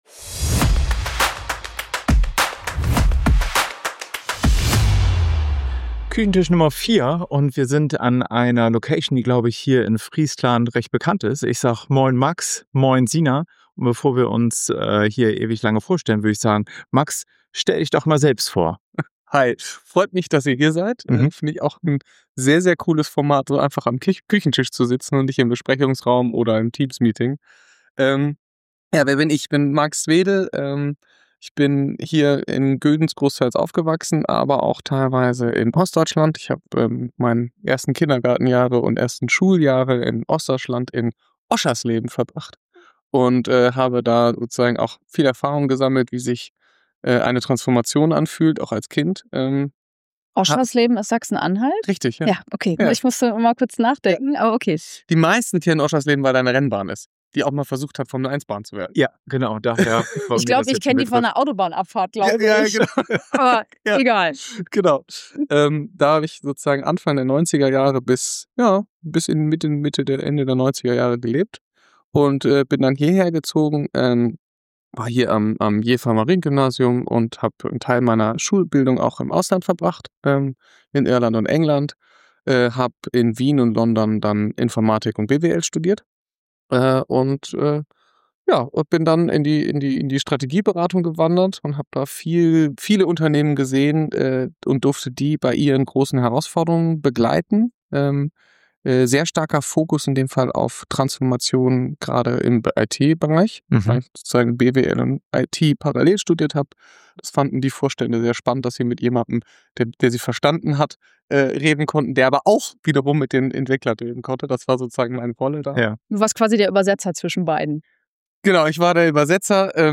Am Küchentisch zählt nicht die Schlagzeile, sondern das echte Gespräch.
Und eines ist klar: Locker und ungefiltert bleibt es immer.